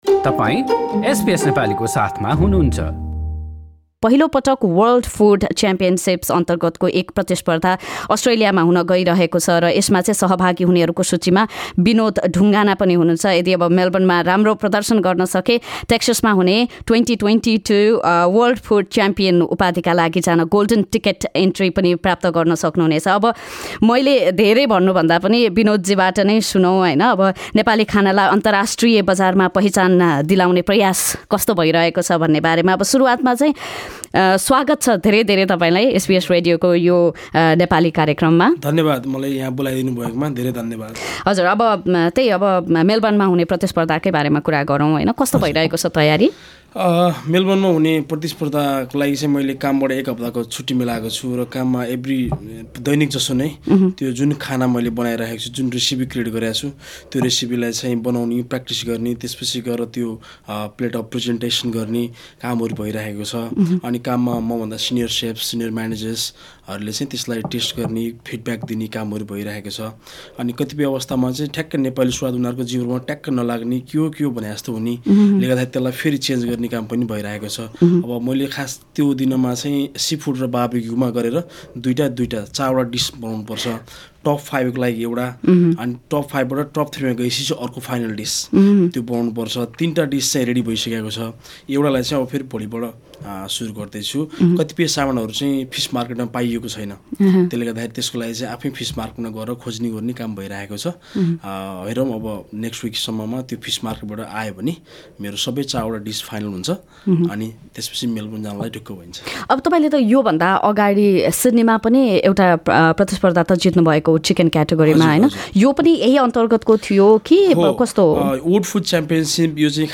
talking to SBS Nepali